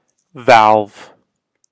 English All dialects valve
[væɫv][citation needed] 'valve' See English phonology
Valve-pronunciation-audio.ogg.mp3